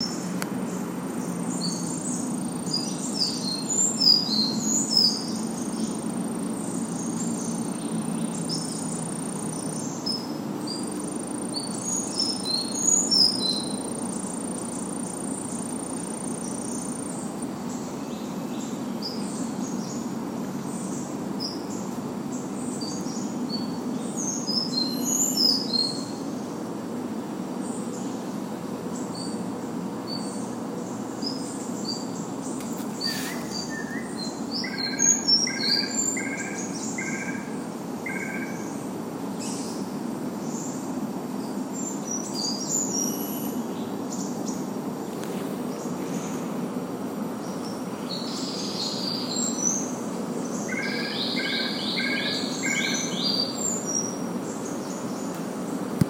On 5 March, during one of these trips up the hill, I heard and immediately recorded what sounded very different from the normal call.
It was an incomplete song, but not at all like the song of the Pale-legged Warbler; instead, it was very similar to the Sakhalin Leaf Warbler.
The Pale-legged Leaf Warbler’s call has a measurably higher pitch than that of the Sakhalin Leaf Warbler.